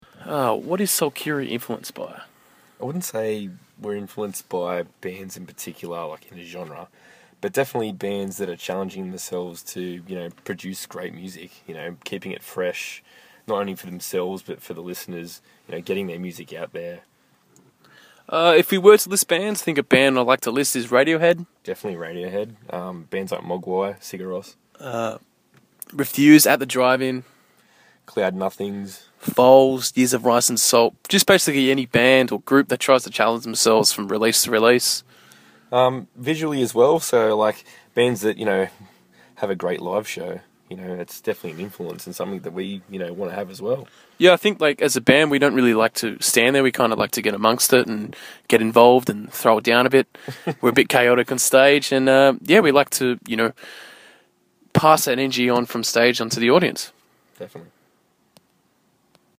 SOLKYRI INTERVIEW – May 2013